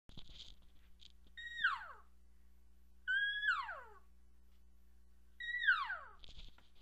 Calf Elk Sounds Long Mew The Elk calves make some of the same sounds as the cows. The difference is the higher pitch and shorter duration than the cow sounds.
long_mew3.wma